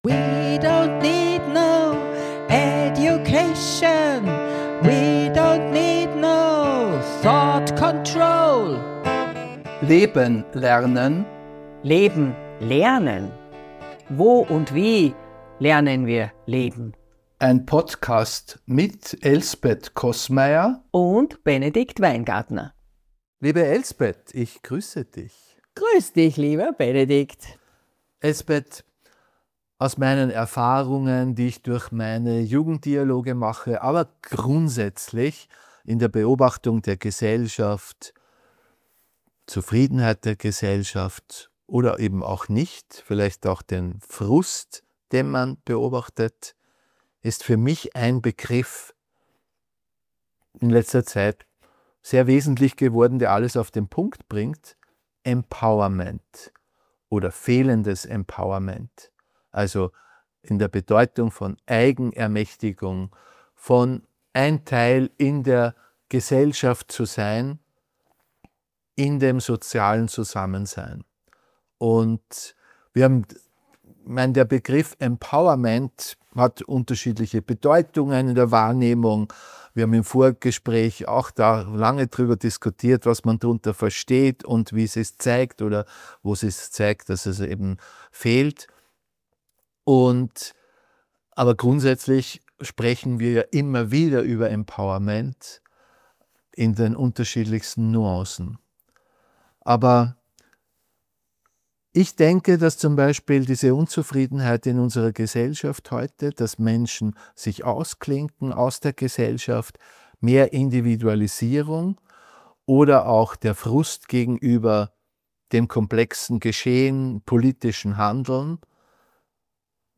Mit ihren unterschiedlichen Zugängen führen sie kritisch und mit utopischen Gedanken im Hinterkopf einen Dialog.